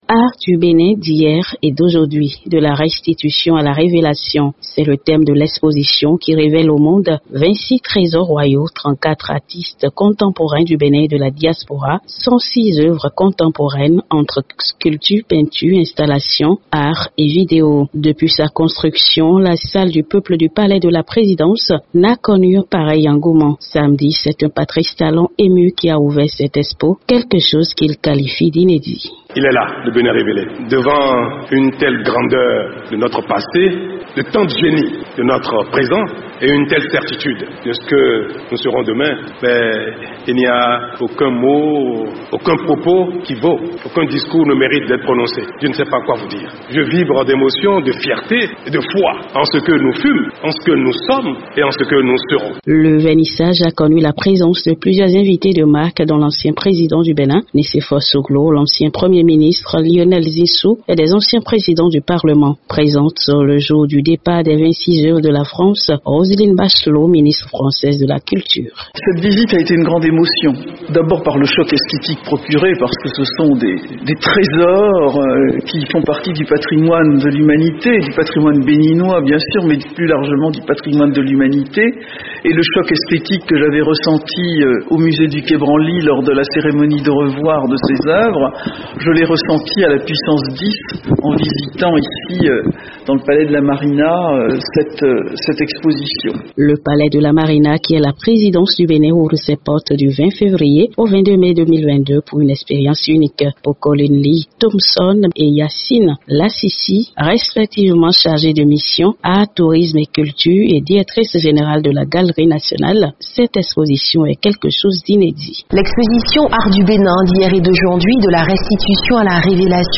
Les 26 trésors royaux rendus par la France, il y a quelques mois, sont exposés au public. Les Béninois sont invités à admirer les œuvres de leurs ancêtres, de retour après 129 ans. Reportage de Cotonou.